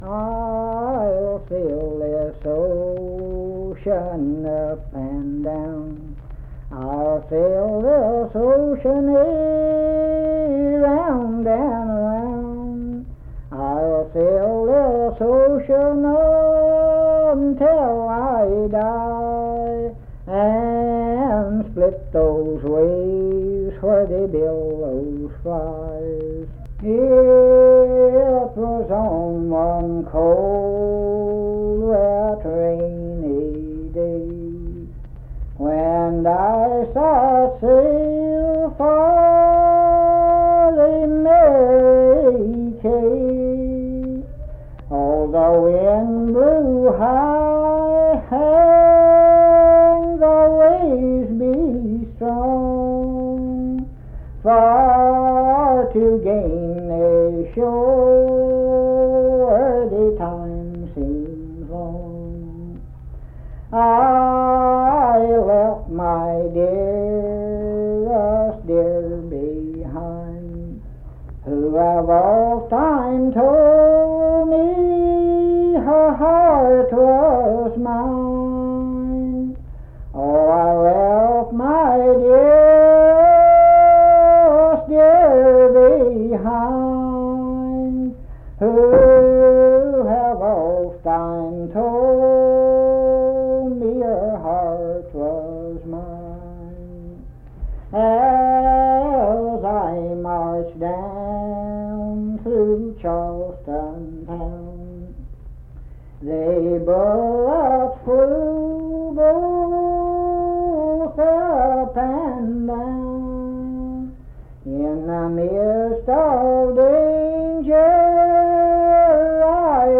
Unaccompanied vocal music
Verse-refrain 8(4). Performed in Naoma, Raleigh County, WV.
Voice (sung)